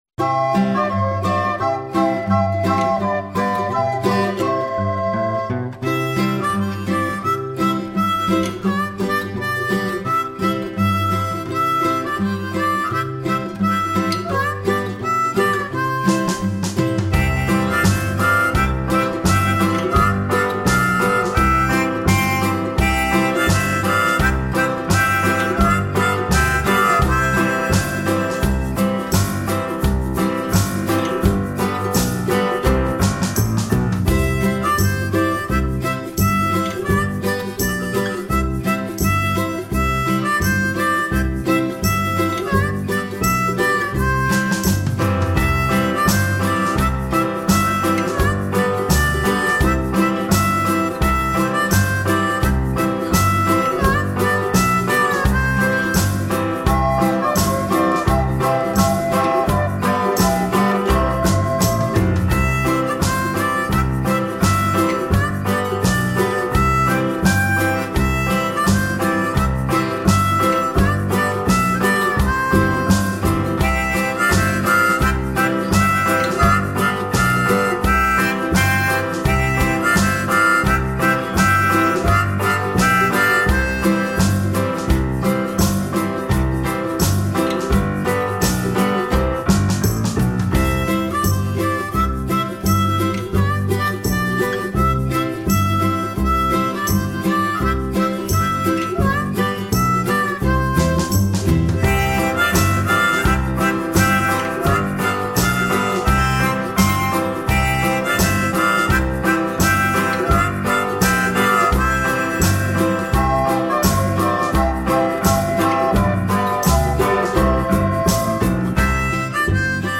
描述：儿童音乐|欢快
Tag: 贝司 钢琴 原声吉他